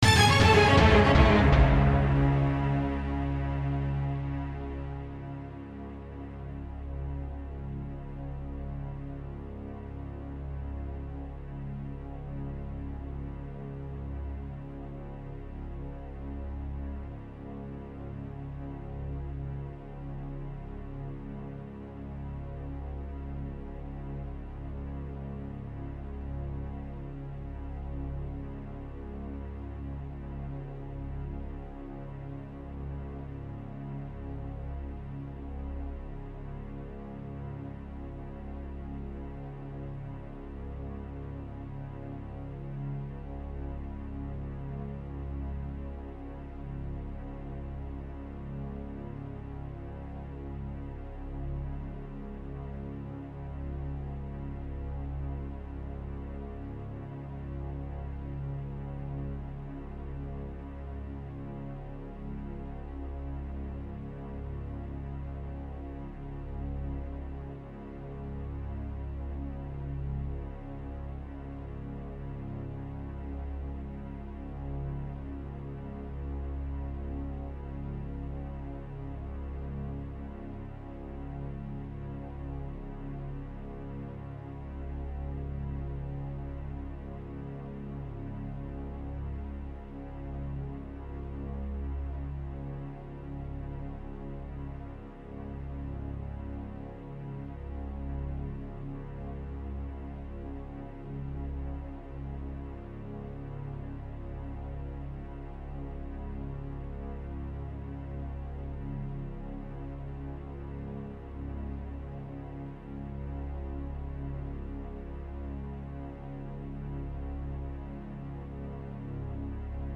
Hudba